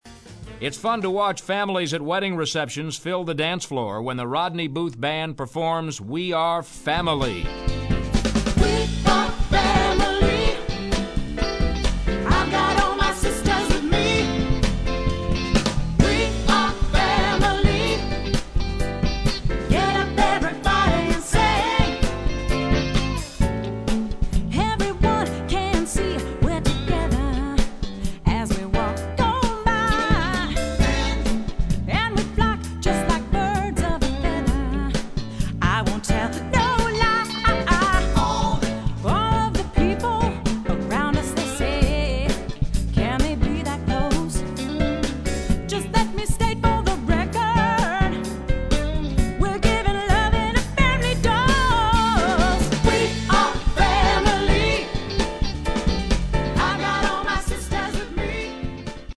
Audio Clips of Big Band